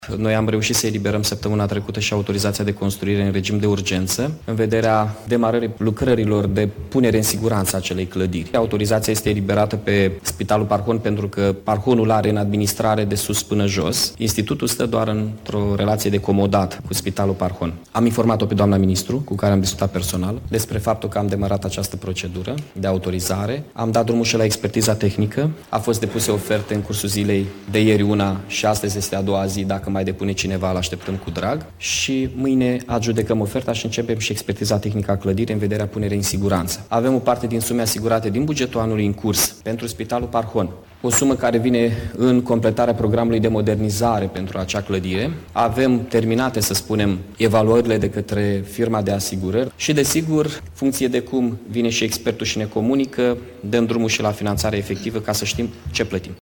Primarul Iaşului, Mihai Chirica, a declarat astăzi, într-o conferinţă de presă că în aceste zile se depun ofertele pentru efectuarea expertizei tehnice în vederea începerii lucrărilor de reparaţii la clădirea Spitalului Parhon, care adăposteşte şi Institutul de Boli Cardiovasculare, imobil grav afectat de incendiul de luna trecută.
Mihai Chirica: